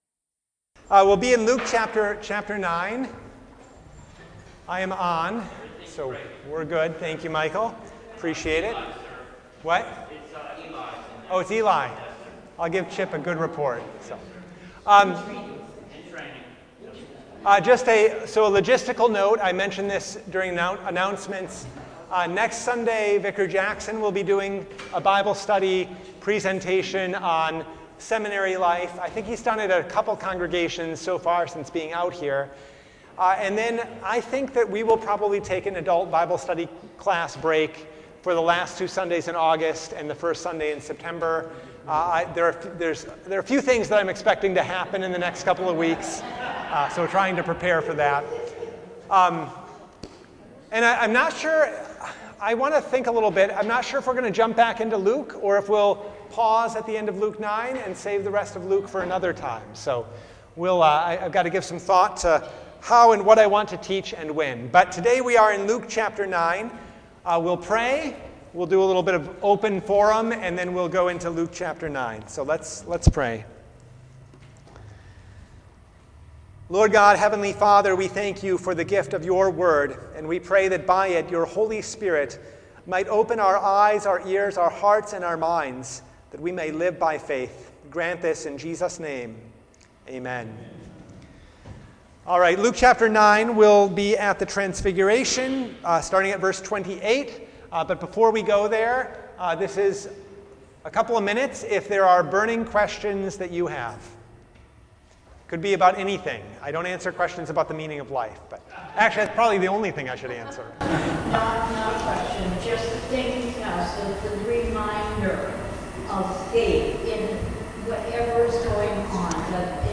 Passage: Luke 9:28-36 Service Type: Bible Study